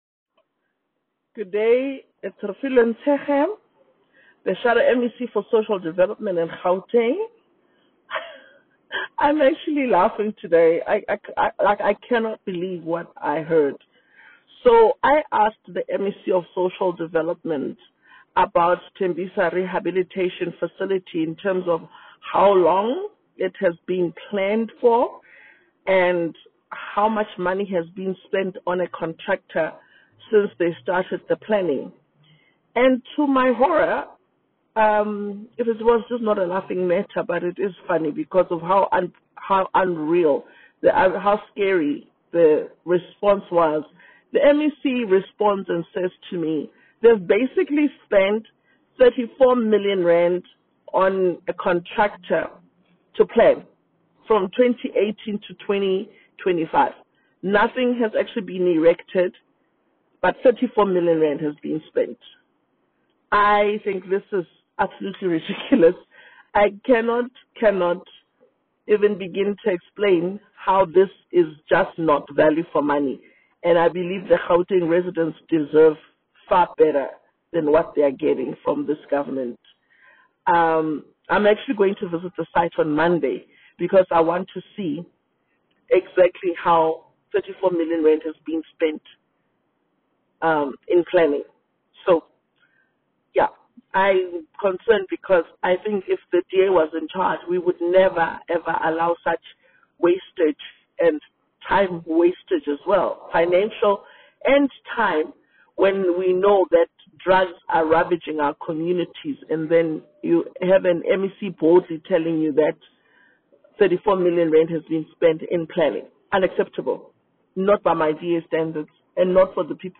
Note to Editors: Attached is a soundbite from DA MPL, Refiloe Nt’sekhe MPL in